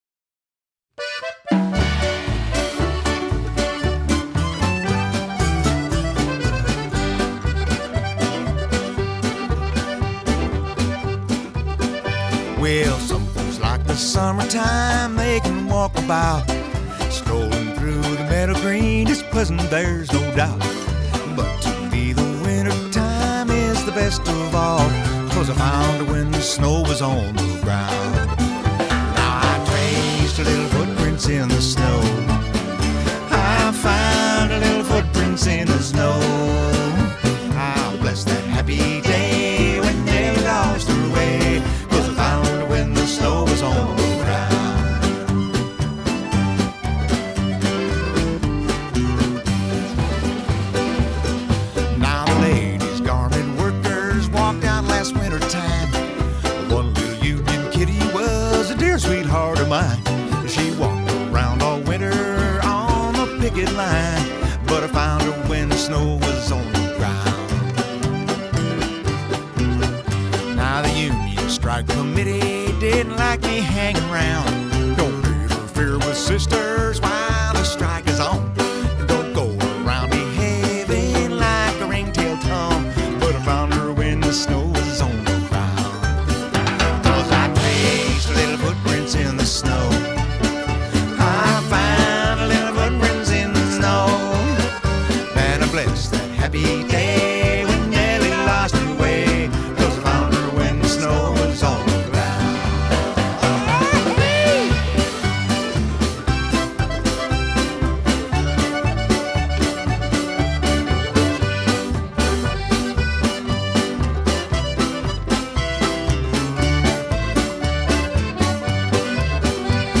Из классики кантри.